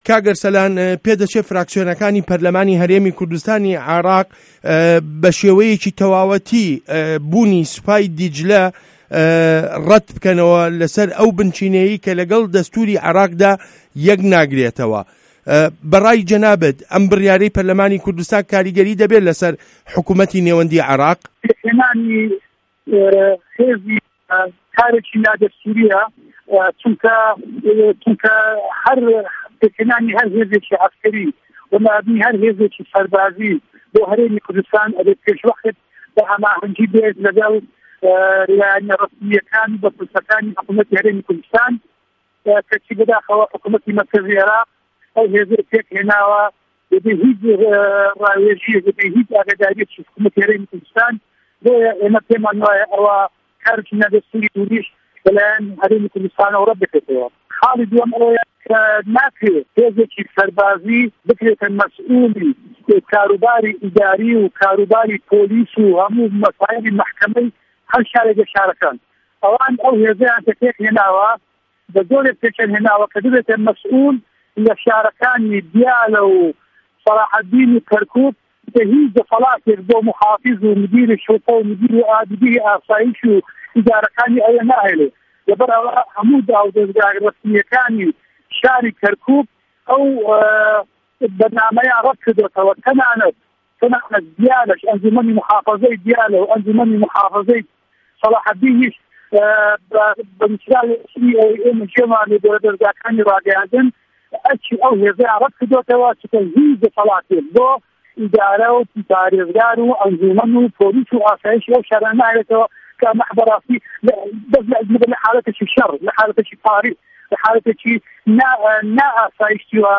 وتووێژ له‌گه‌ڵ ئه‌رسه‌لان بایز